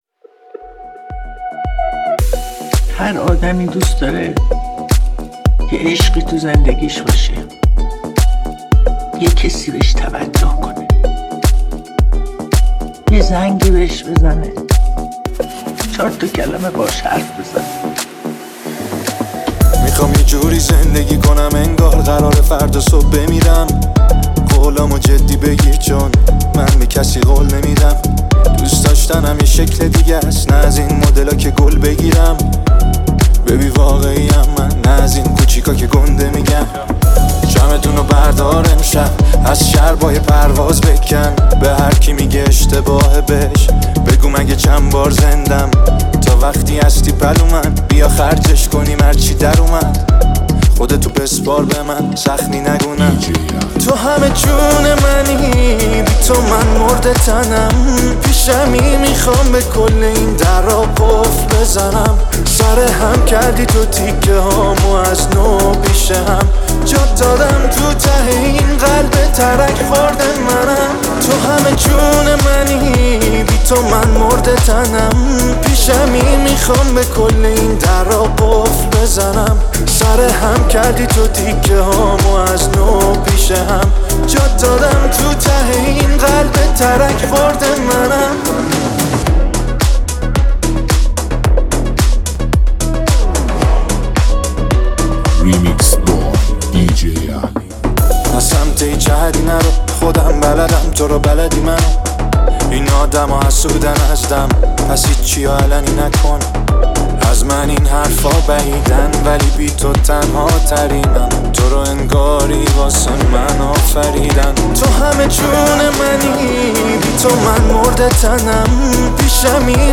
(تند بیس دار)